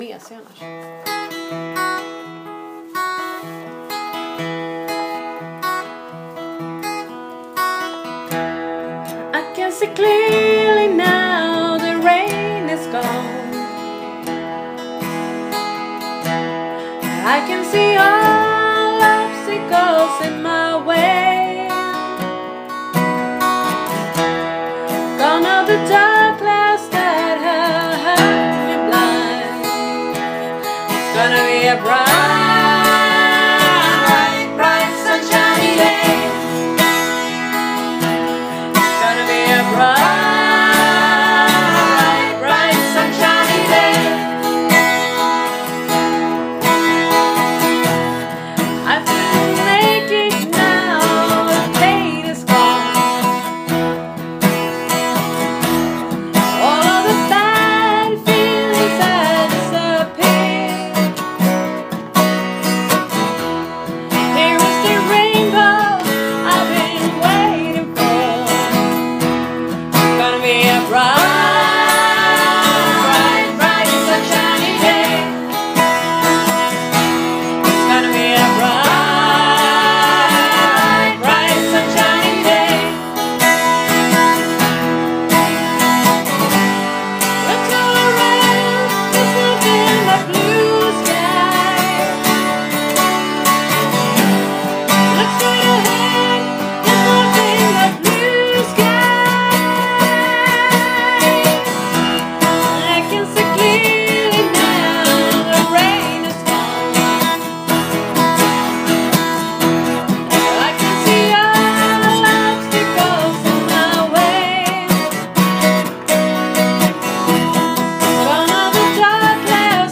Unplugged
Today we had unplugged rehearsal.
Here’s a memo from today recorded with my Iphone.